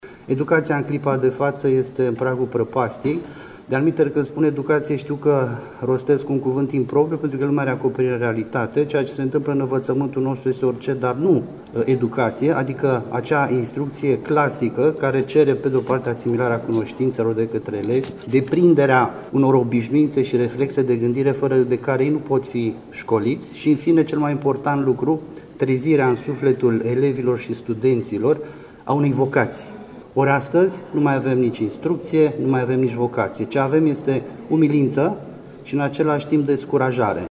S-a lucrat însă la textul moțiunii, prezentat azi în conferință de presă de senatorul Sorin Lavric.